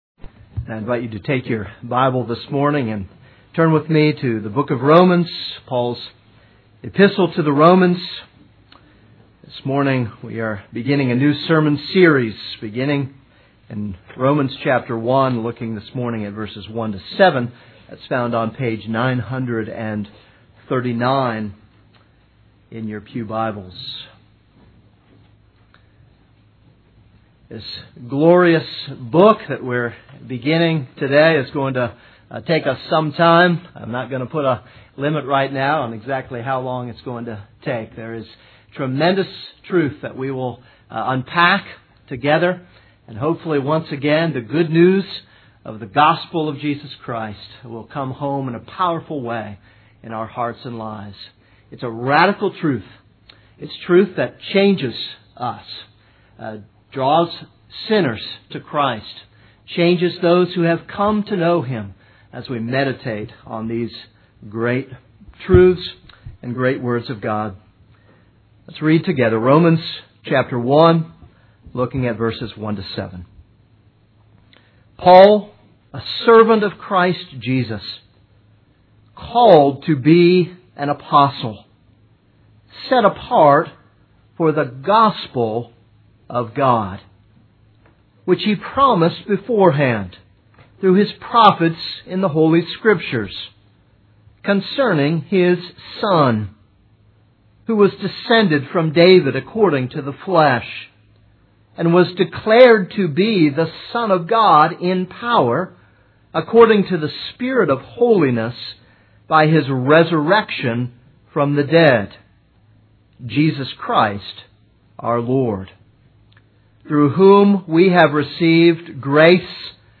This is a sermon on Romans 1:1-7.